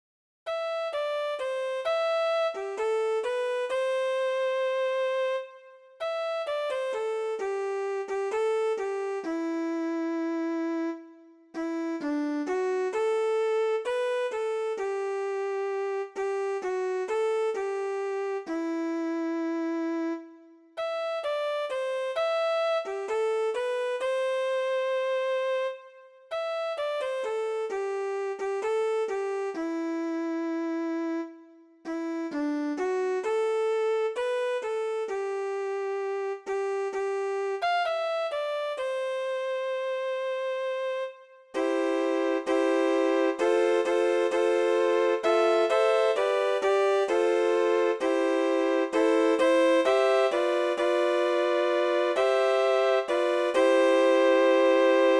THÁNH CA